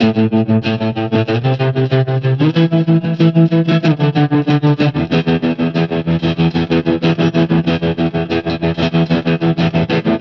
Trem Trance Guitar 01f.wav